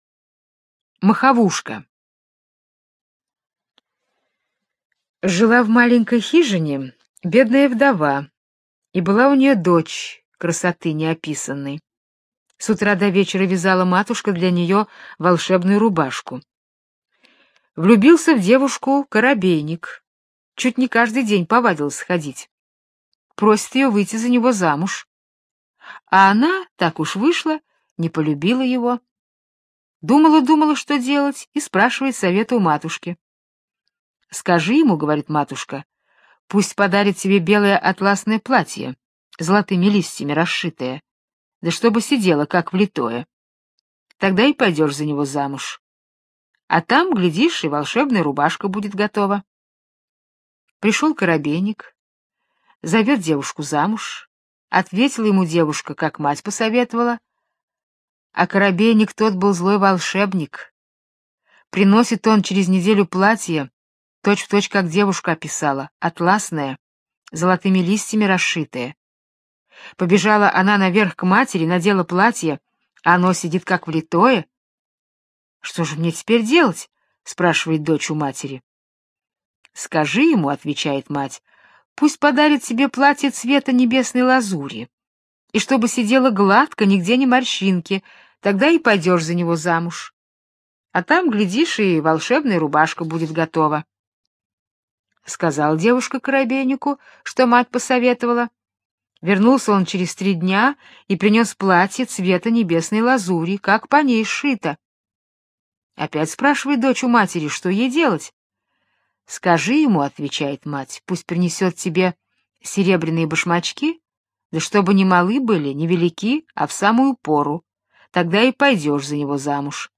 Моховушка - британская аудиосказка - слушать онлайн